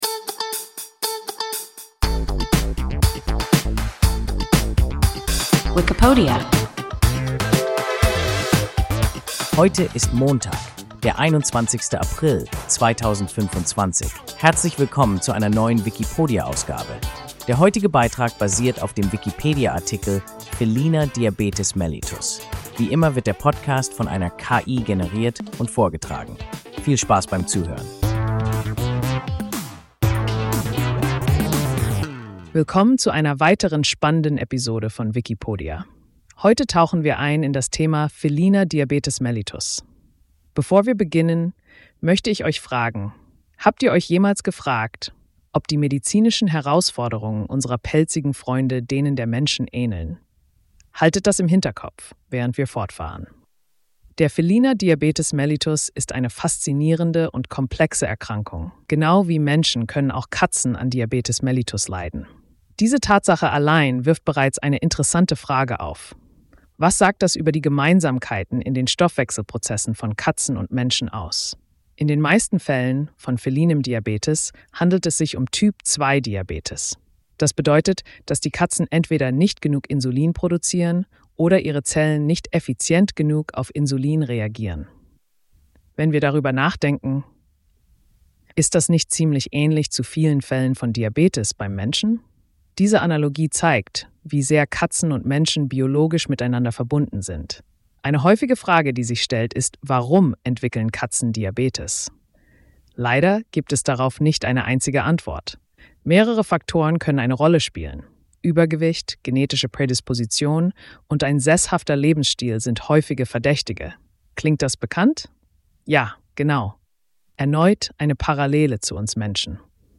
Feliner Diabetes mellitus – WIKIPODIA – ein KI Podcast